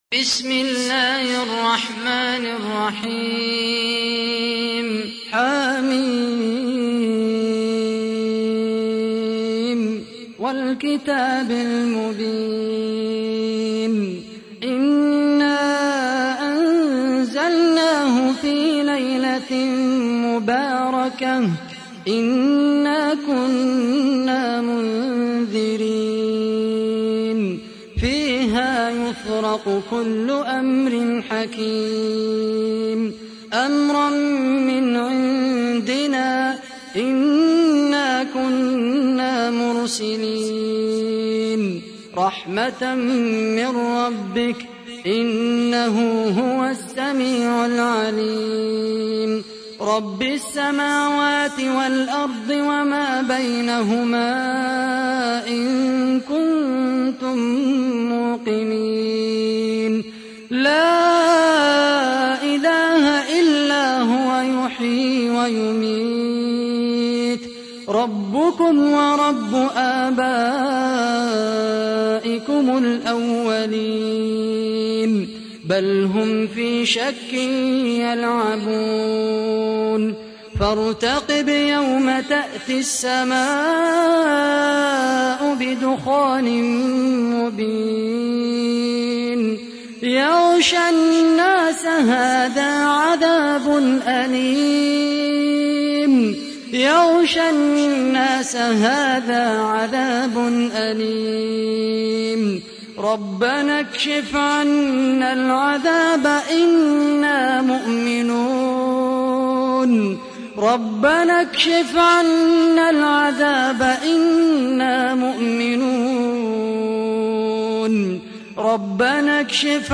تحميل : 44. سورة الدخان / القارئ خالد القحطاني / القرآن الكريم / موقع يا حسين